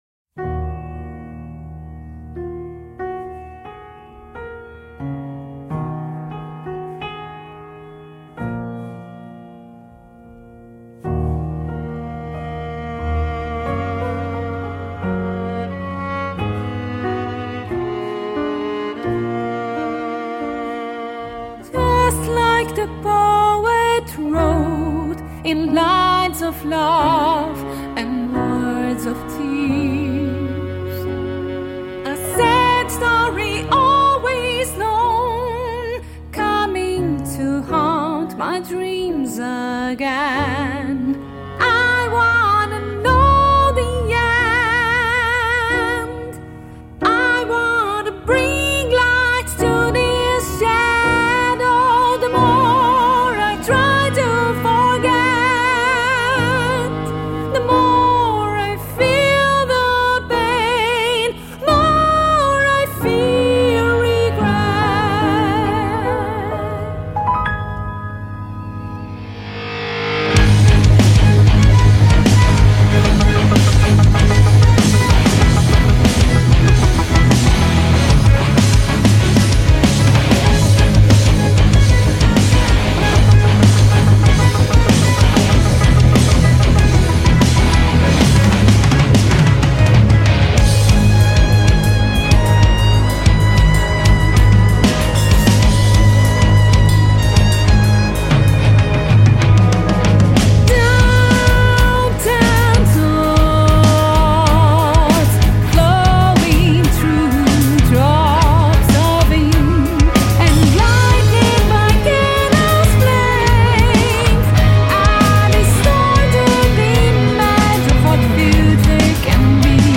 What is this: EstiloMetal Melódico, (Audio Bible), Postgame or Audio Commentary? EstiloMetal Melódico